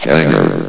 Unmodularize vox sounds
hangar.ogg